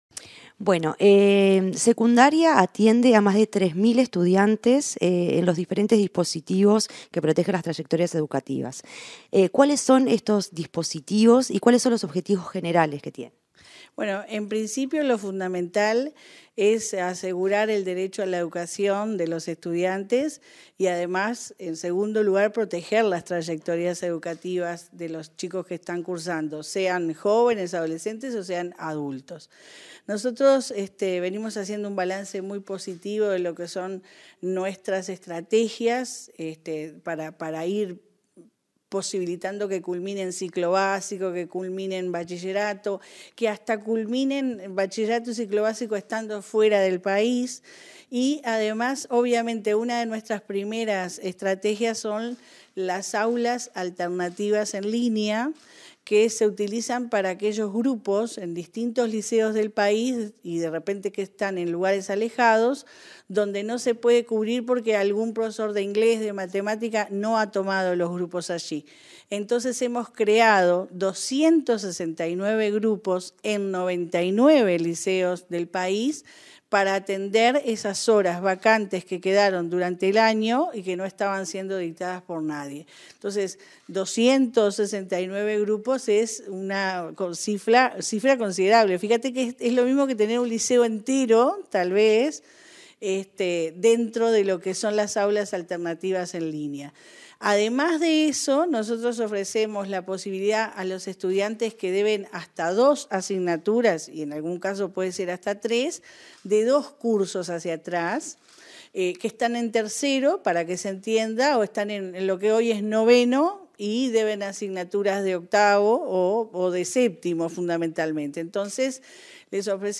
Entrevista a la directora general de Secundaria, Jenifer Cherro